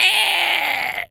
bird_large_squawk_06.wav